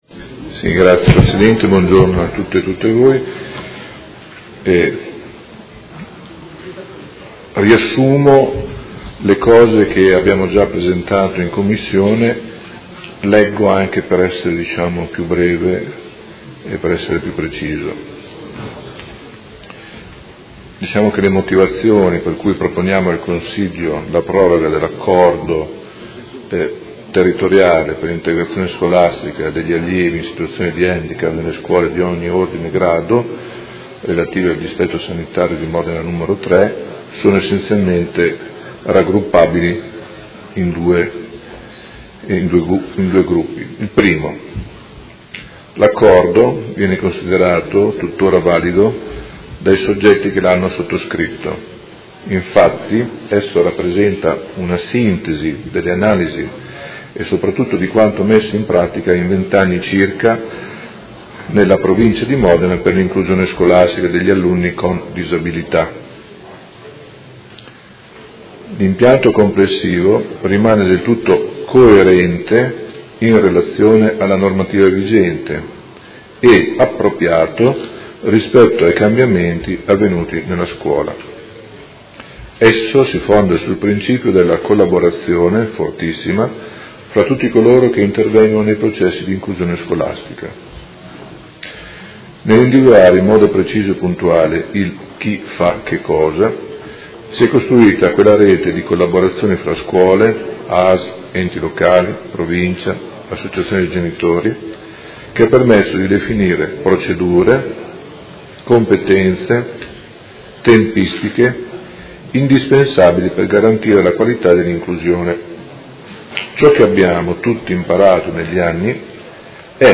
Gianpietro Cavazza — Sito Audio Consiglio Comunale